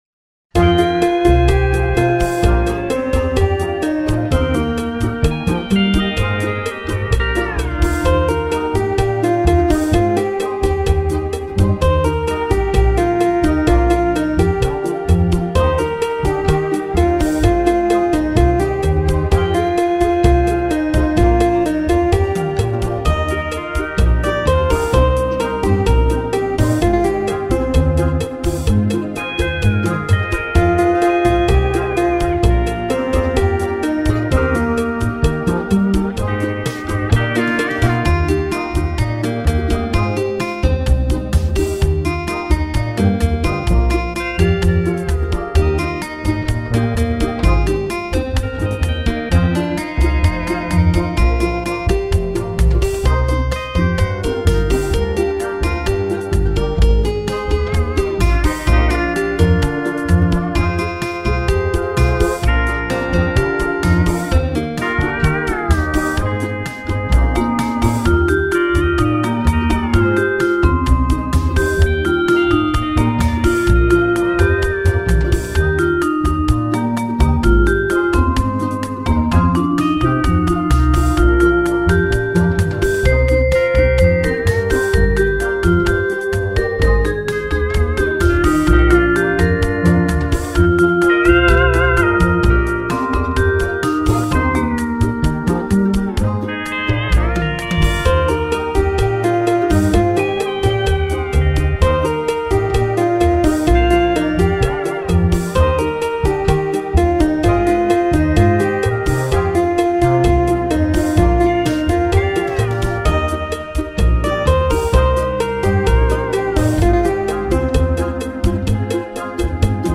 Low Key